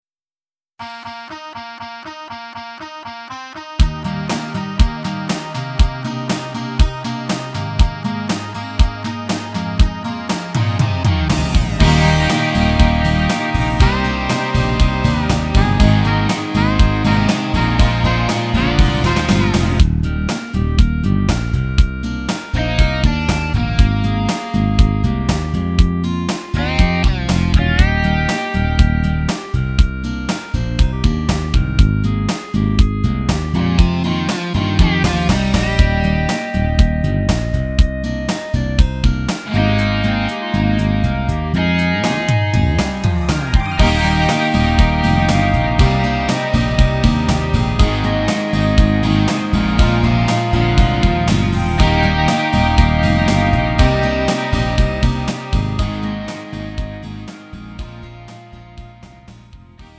음정 원키 2:45
장르 구분 Lite MR